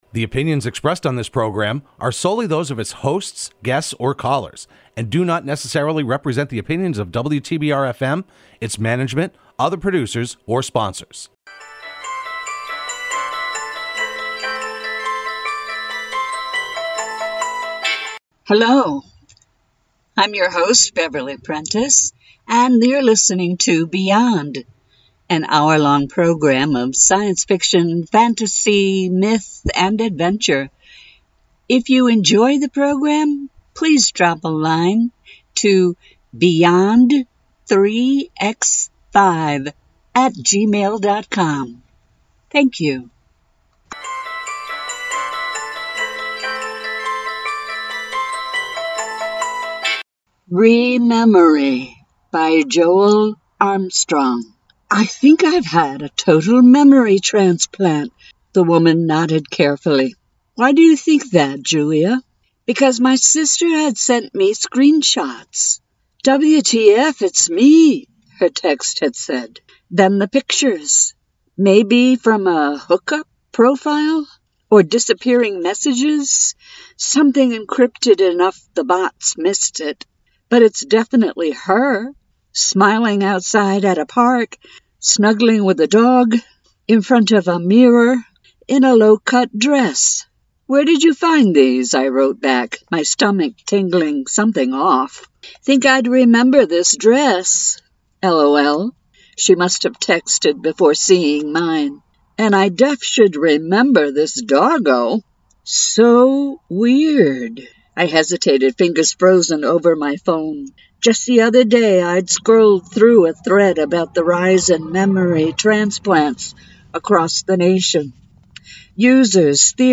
reads some short stories